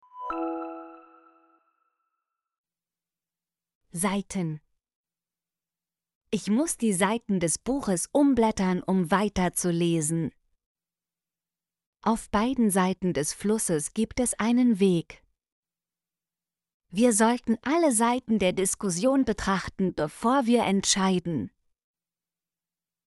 seiten - Example Sentences & Pronunciation, German Frequency List